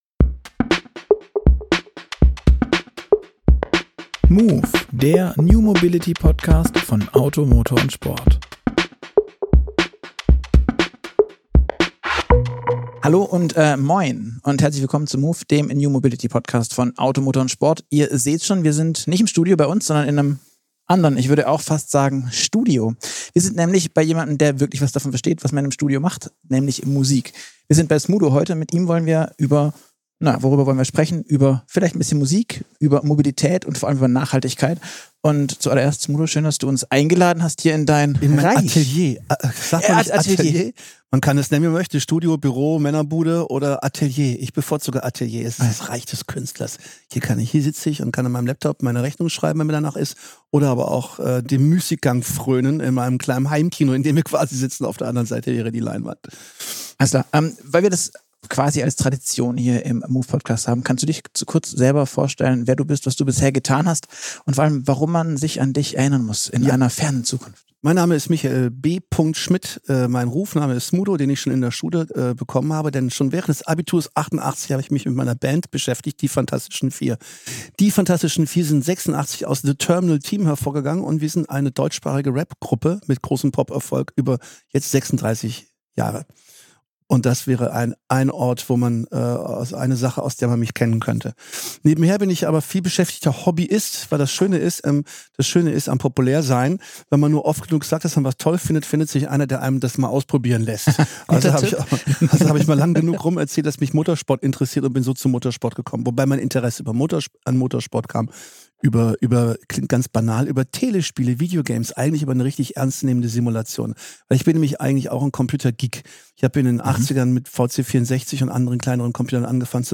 Im Moove-Podcast zu Gast: Smudo – Musiker, Motorsport-Enthusiast und Nachhaltigkeits-Pionier.